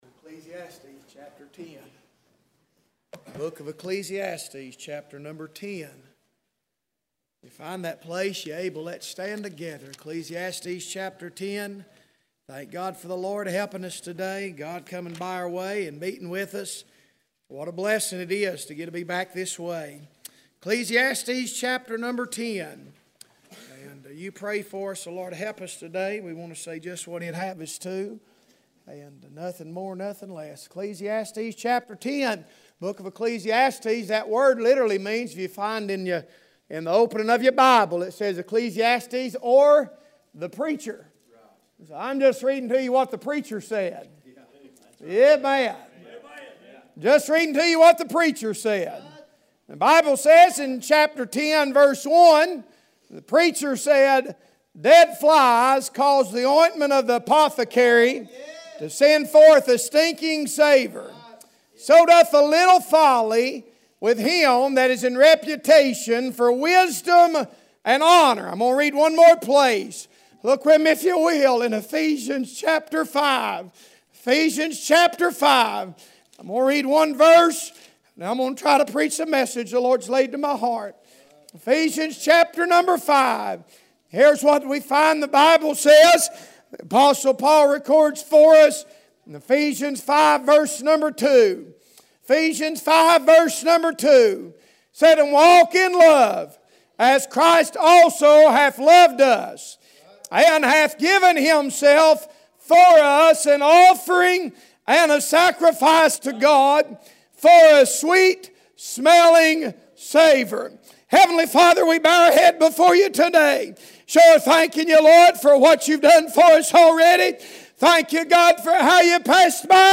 Passage: Ecclesiastes 5:1; Ephesians 5:2 Service Type: Sunday Morning